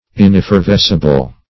Search Result for " ineffervescible" : The Collaborative International Dictionary of English v.0.48: Ineffervescible \In*ef`fer*ves"ci*ble\, a. Not capable or susceptible of effervescence.
ineffervescible.mp3